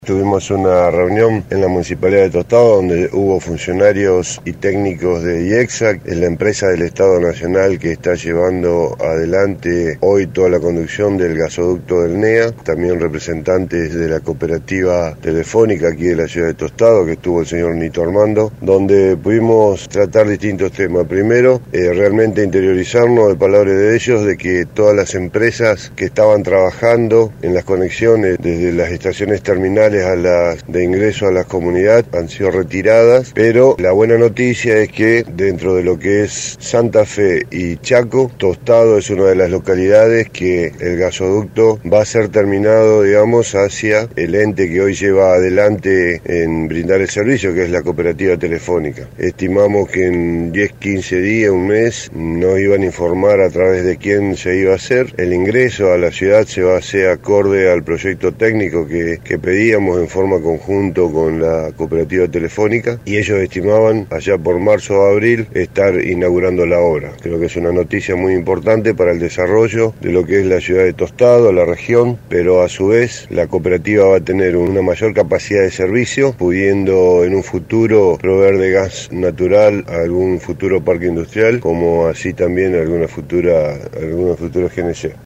El intendente Enrique Mualem brindó detalles de la reunión: